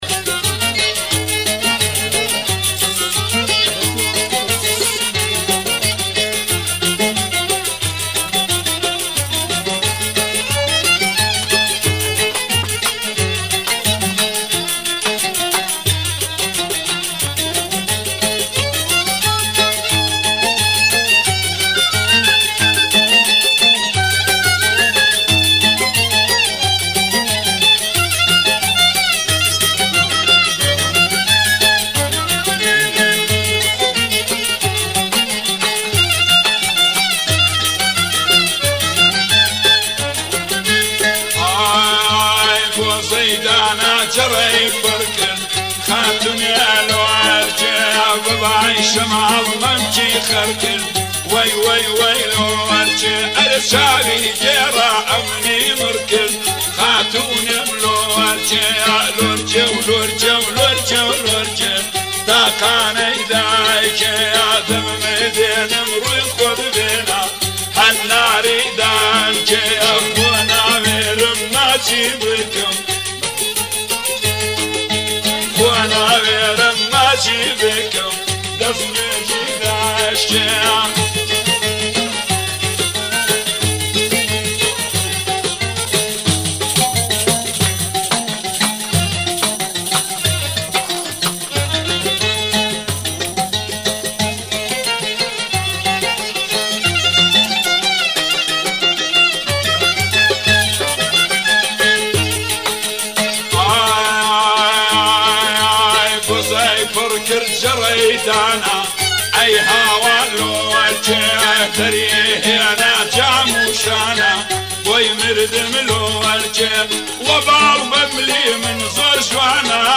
این آهنگ کردی